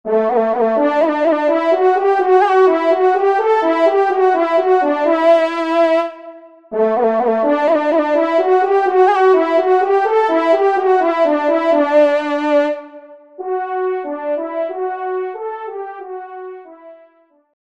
Genre : Fantaisie Liturgique pour quatre trompes
Pupitre 1° Trompe